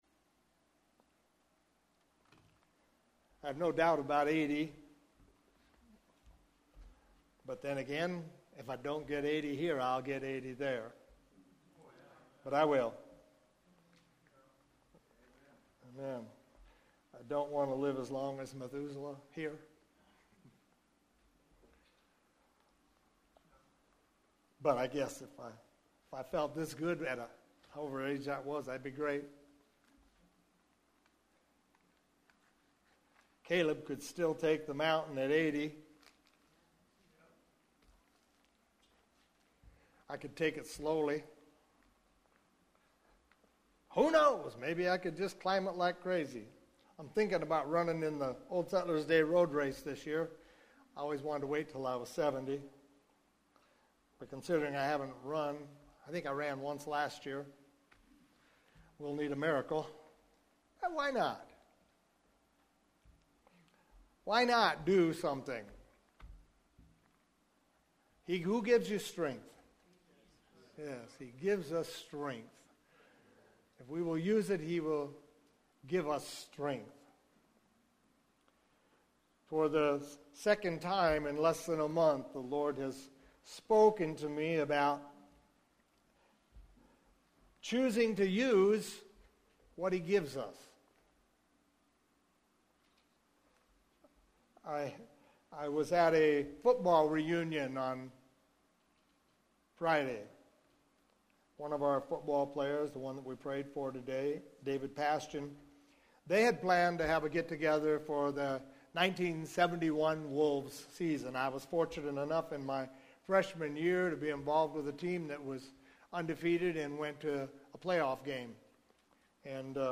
Longer sermons are broken up into smaller...